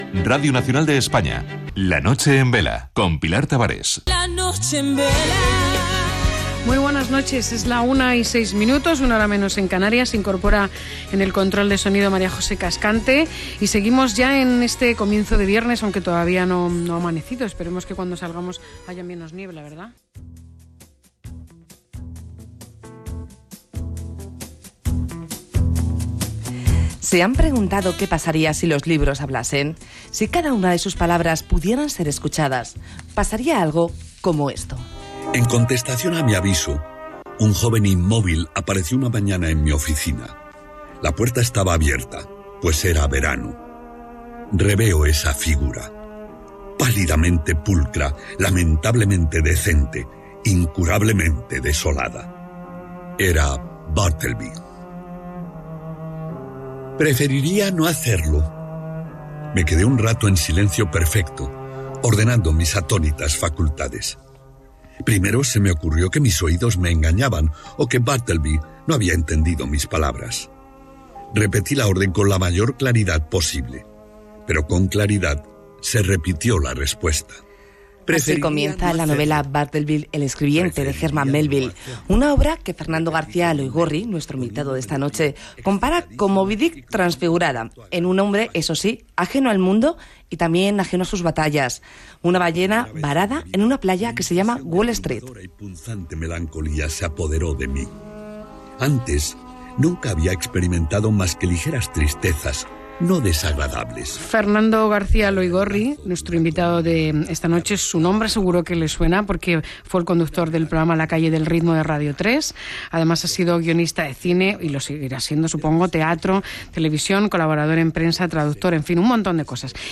Entrevista en Radio Nacional de España
Esta es la entrevista.